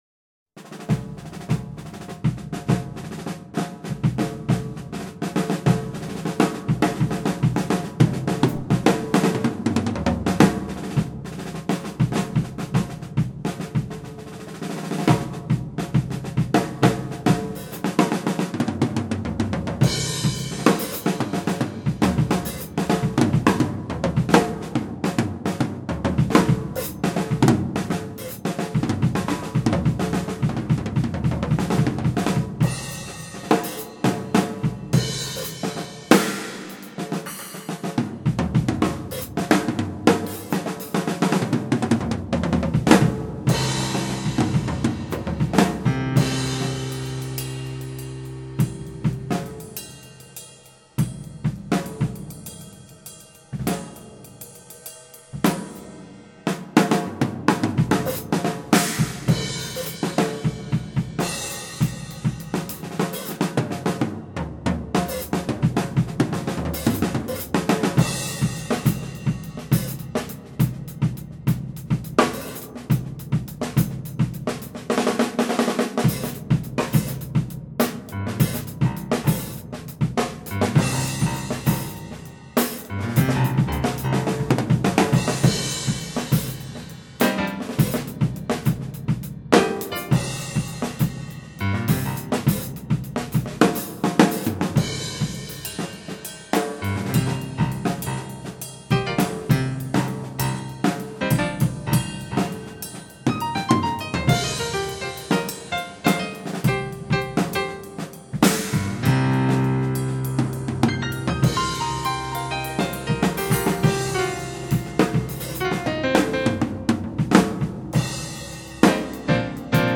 Improv
It is very messy but there are some moments where we gel nicely. It was recorded with one mic after a 14 hour recording session.